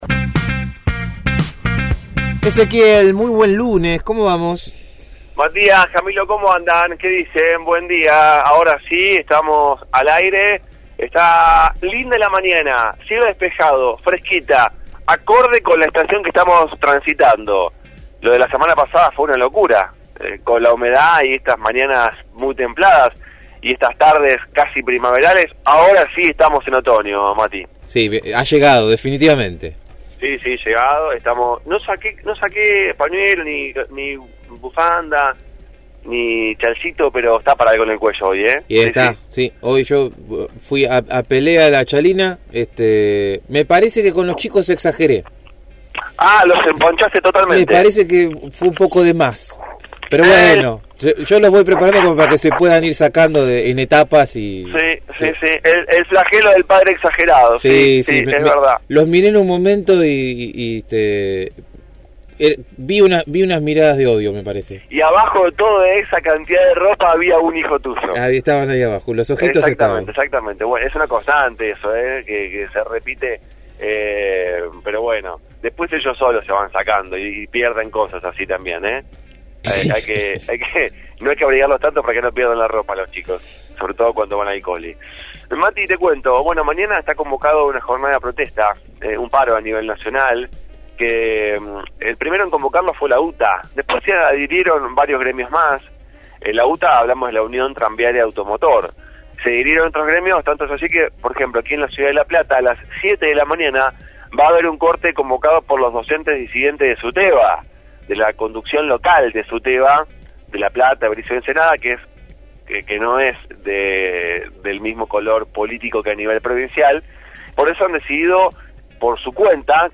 MÓVIL/ Paro de transportes convocado para el martes